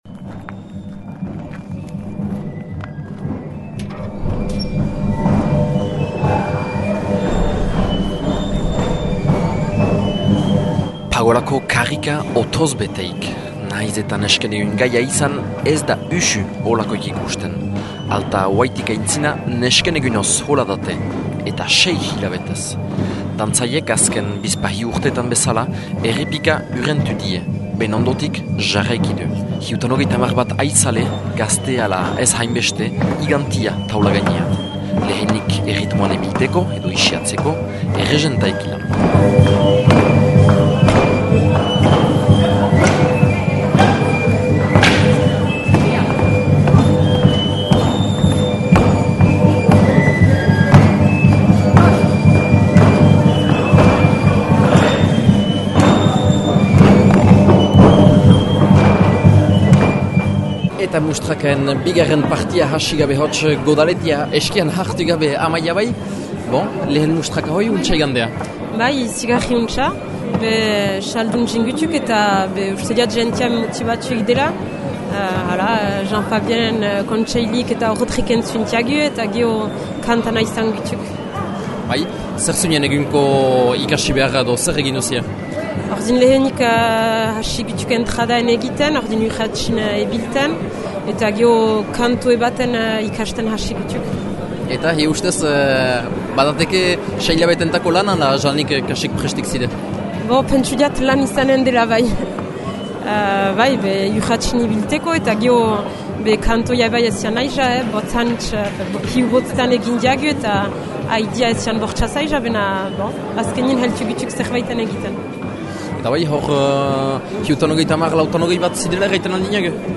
Domingo Garat Pastoraleko lehen mustraka egin düe. 70bat arizale hüllantu dira, beste hainbat ikusleren artean. Erritmoan ebiltea eta lehen kantorearen ikasten hasi dira bertan.